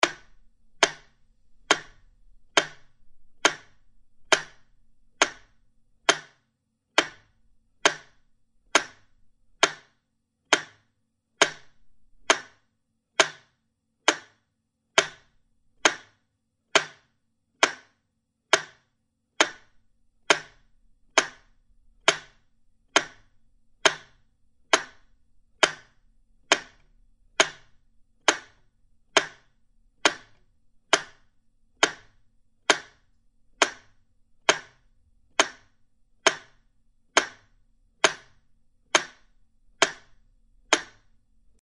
Metronome.mp3